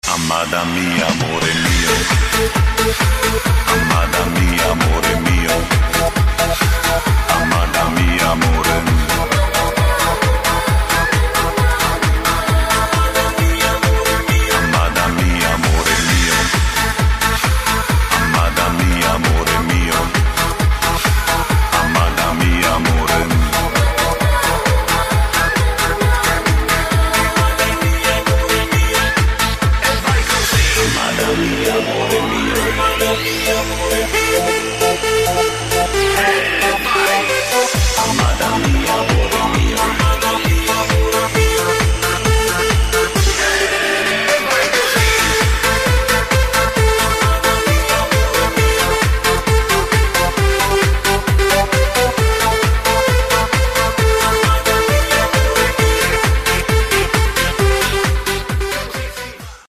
мужской вокал
женский вокал
Electronic
электронная музыка
чувственные
italodance